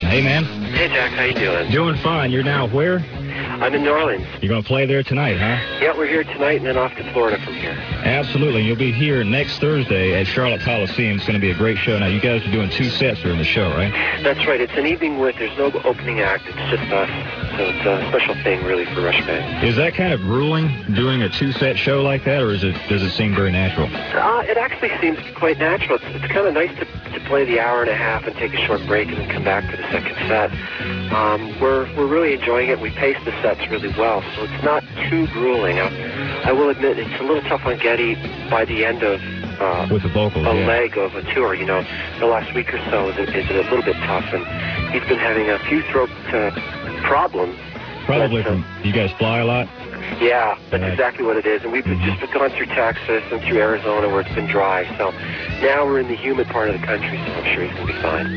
On Friday, December 6, 1996 Alex Lifeson called in to WRFX 99.7 FM in Charlotte, NC.